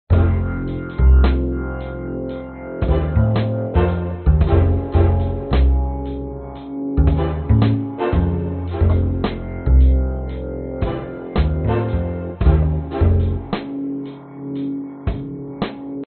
标签： 嘻哈 循环 老式学校
声道立体声